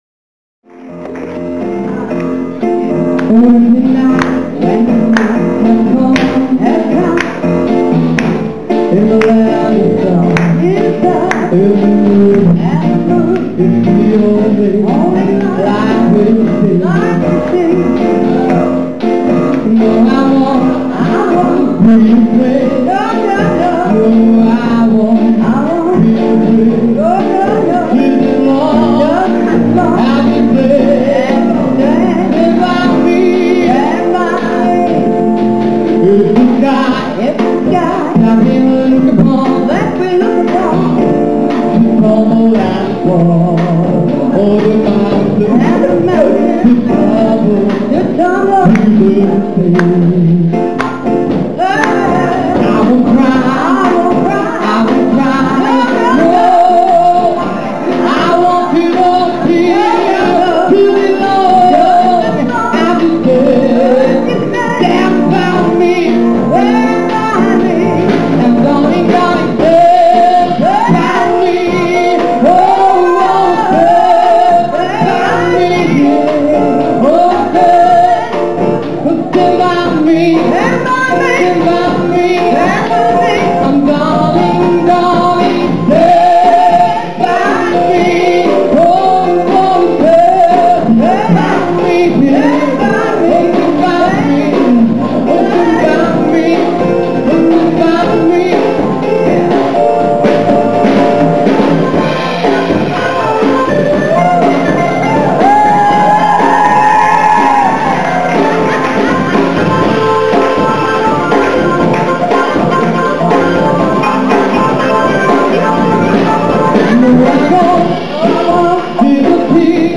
zu den Audiofiles (aufgenommen auf der Aftershow-Party):